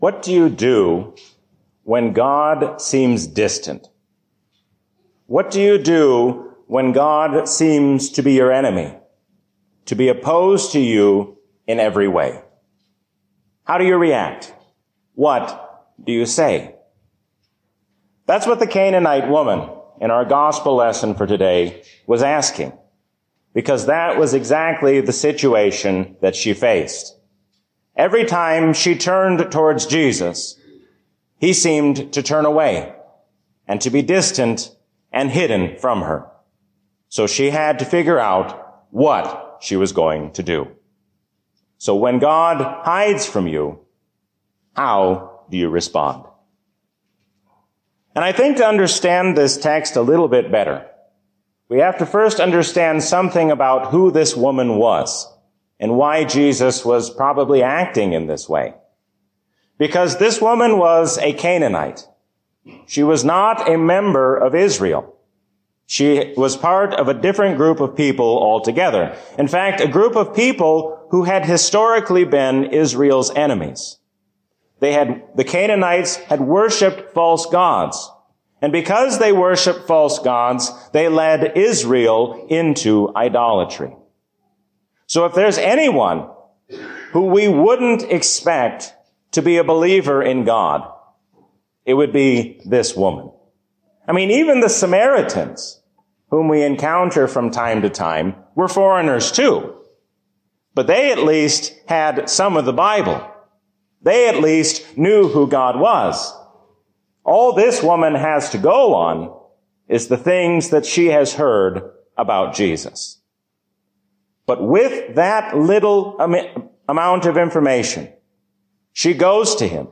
A sermon from the season "Trinity 2024." There is no reason to worry about Tuesday or any day to come when we remember that the Lord reigns as King forever.